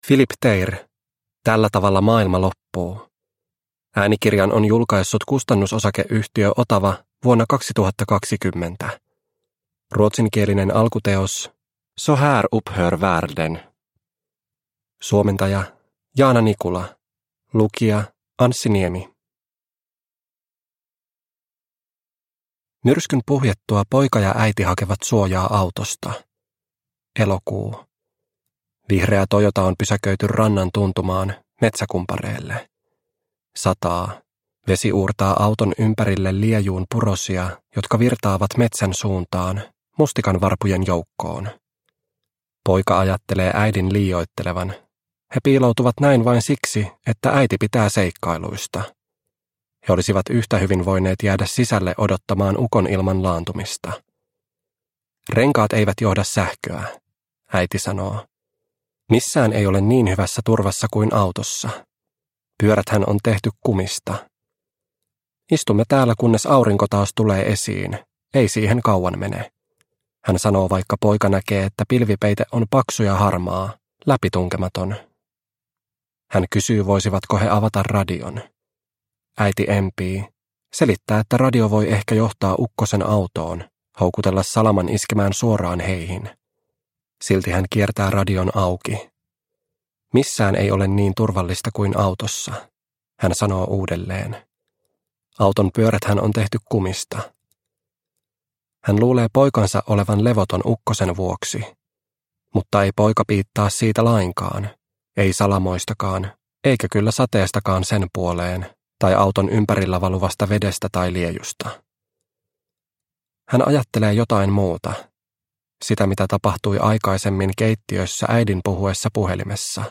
Tällä tavalla maailma loppuu – Ljudbok – Laddas ner